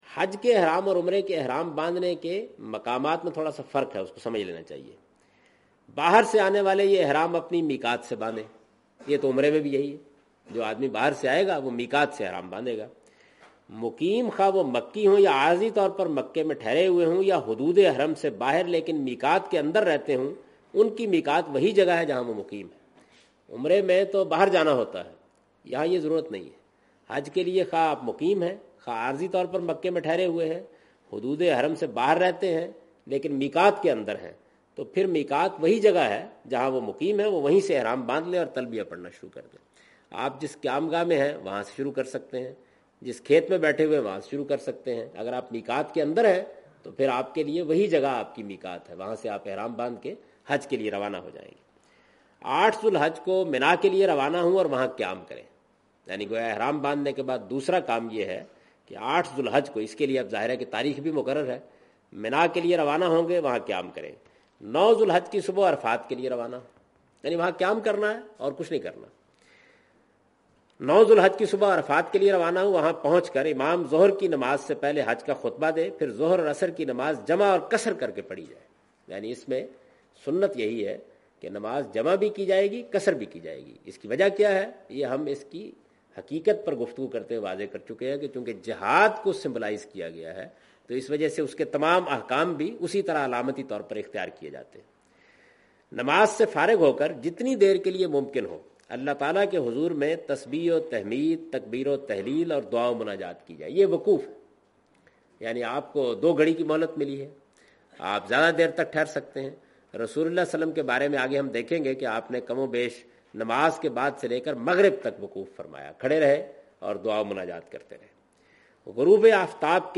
In this video of Hajj and Umrah, Javed Ahmed Ghamdi is talking about "Hajj Rituals".
حج و عمرہ کی اس ویڈیو میں جناب جاوید احمد صاحب غامدی "مناسک حج" سے متعلق گفتگو کر رہے ہیں۔